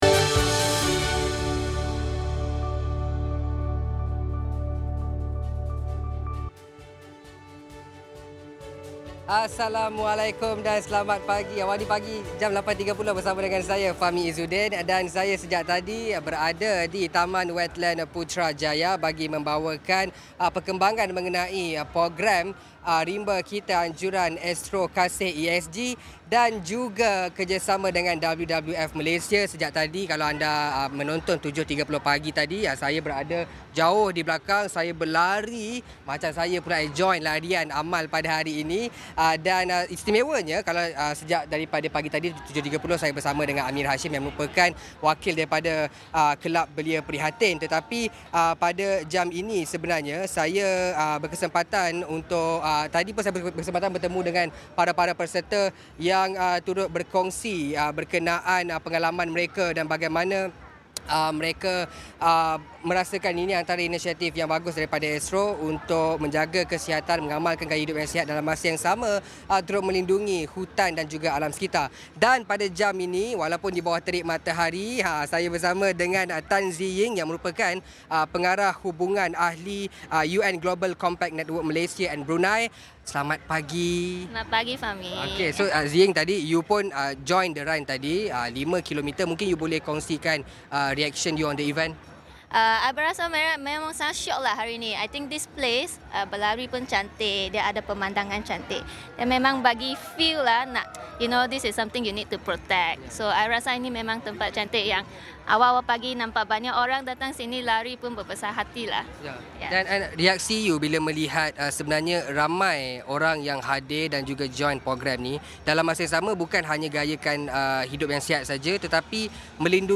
bersiaran langsung dari Taman Wetland, Putrajaya